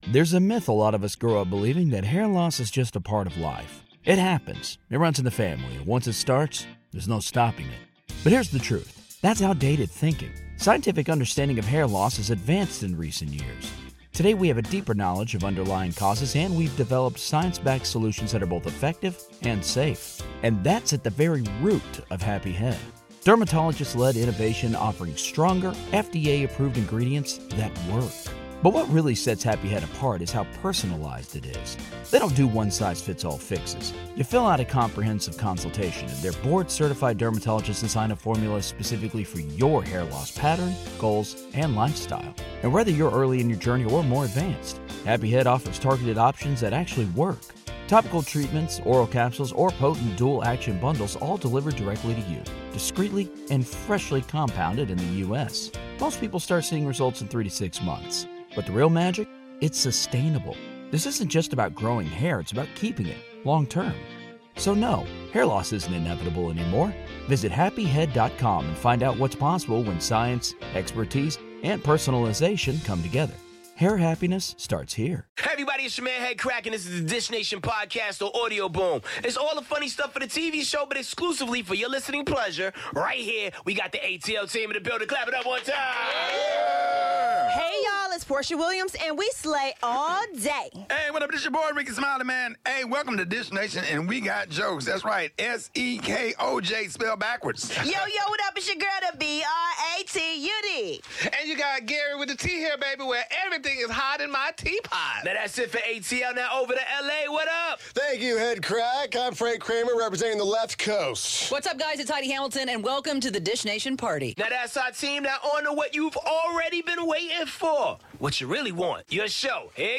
Guest co-host: Monie Love. We dish with Mark Wahlberg, Josh Duhamel and the cast of 'Transformers' and what is 'Hot Felon' Jeremy Meeks going to do next?